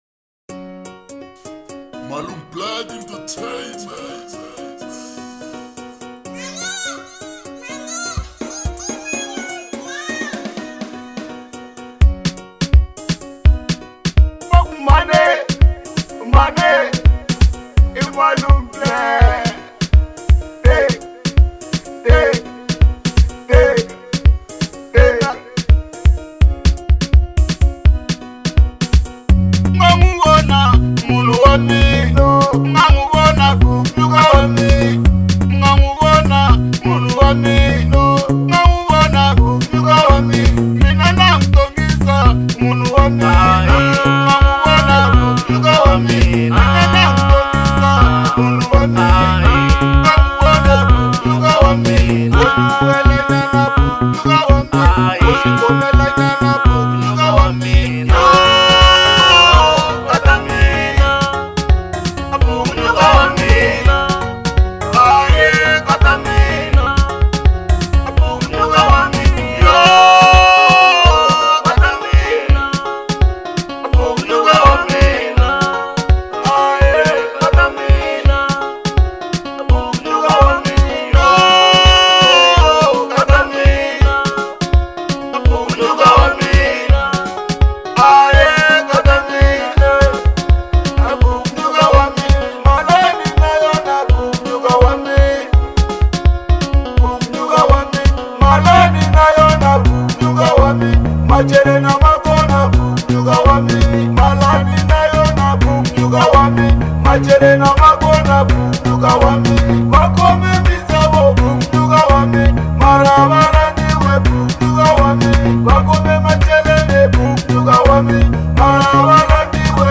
03:42 Genre : Marrabenta Size